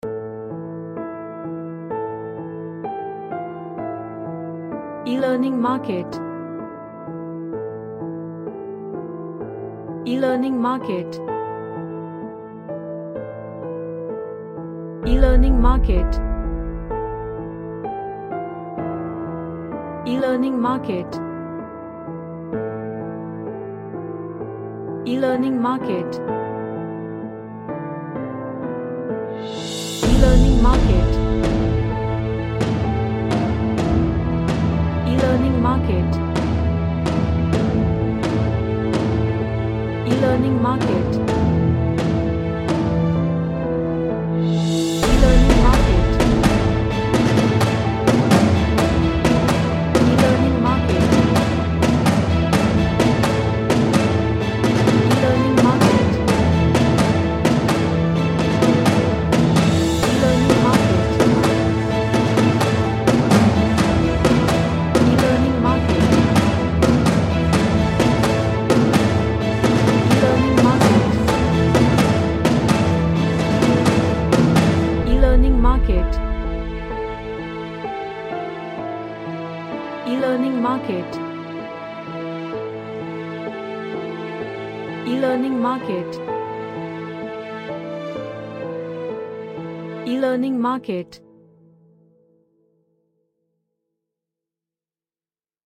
A war type orchestral music
Epic / Orchestral